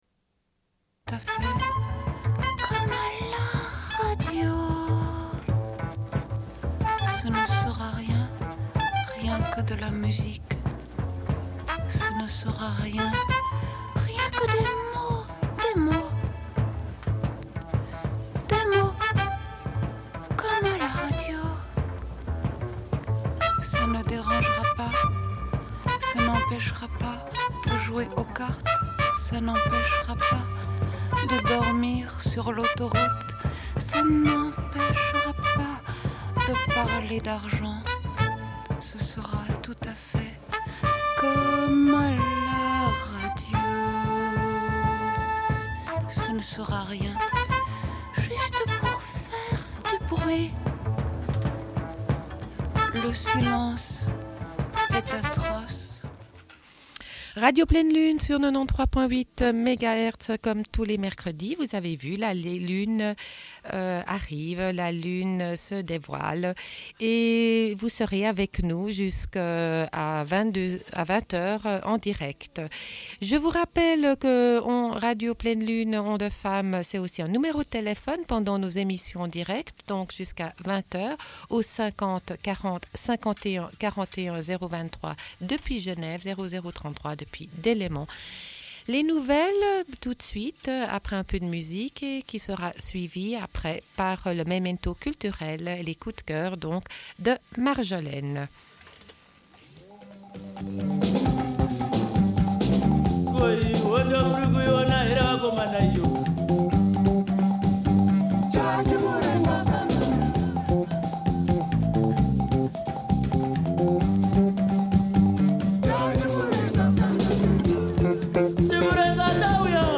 Une cassette audio, face B29:50